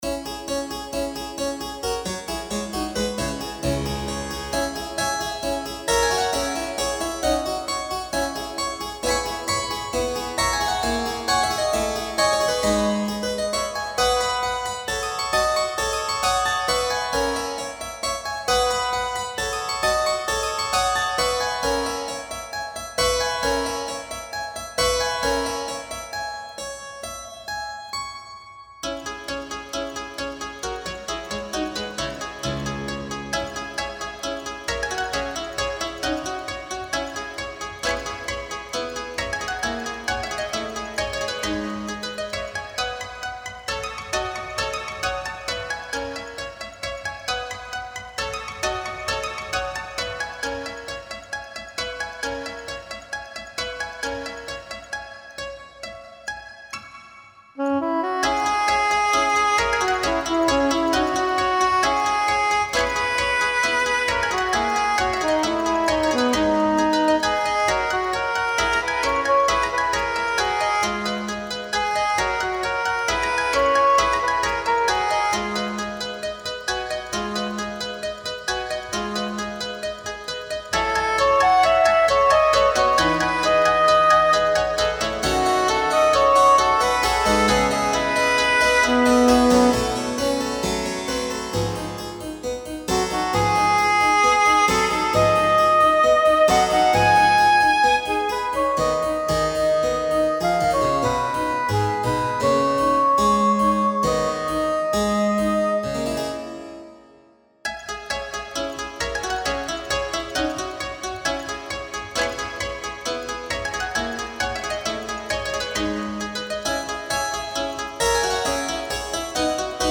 Daneben, mit leichter Ironie, setzte Ravel mit „D’Anne jouant de l’espinette“ („Anne spielt Cembalo“) auch ein kurzes Stück für Harfen und Bariton um – wir haben sie durch zwei Cembali und ein virtuelles Sopransaxophon ersetzt. Außerdem kommt im Stück auch der Lautenzug des virtuellen Cembalos zum Einsatz:
‚D’Anne jouant de l’espinette‘ für virtuelle Cembali und Sopransaxophon